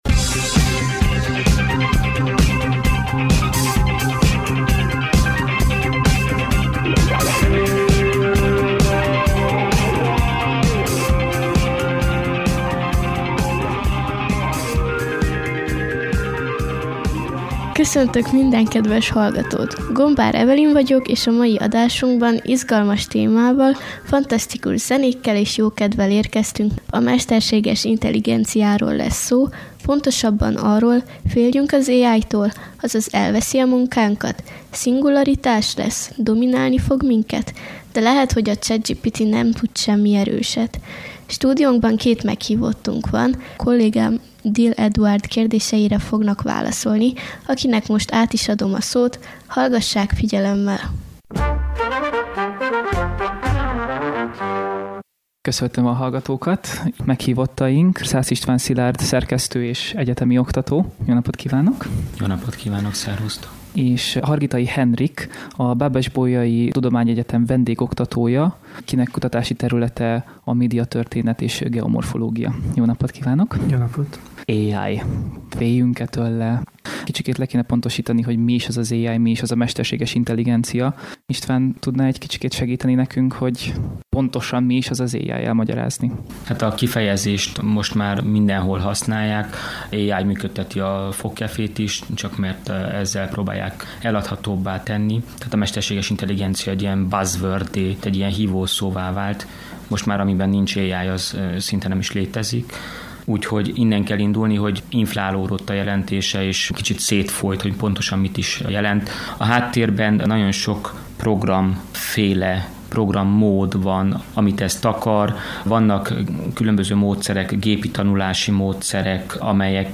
Sőt, a szakértőket is ők keresték a beszélgetéshez: azok közül választottak, akik az egyetemen hasonló dolgokat tanítottak nekik.